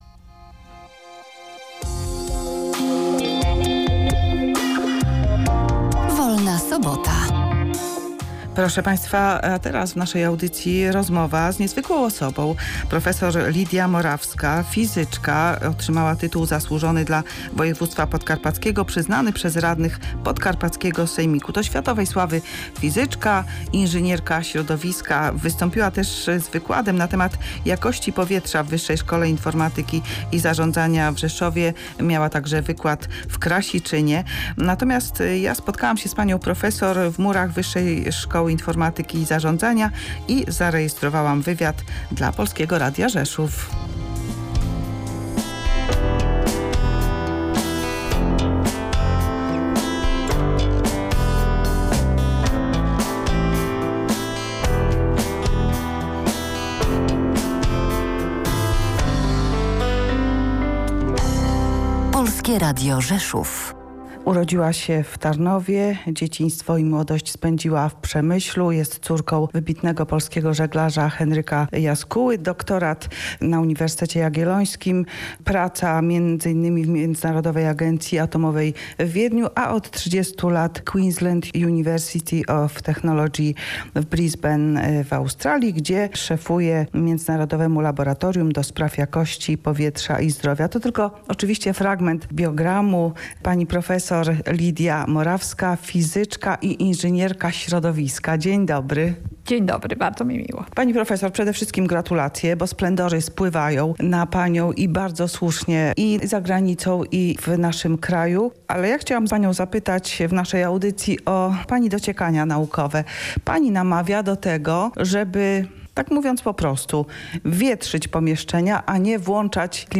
W audycji Wolna Sobota gościliśmy światowej sławy fizyczkę prof. Lidię Morawską, córkę wybitnego żeglarza Henryka Jaskuły, ekspertkę w dziedzinie jakości powietrza, która opowiadała o swoich badaniach.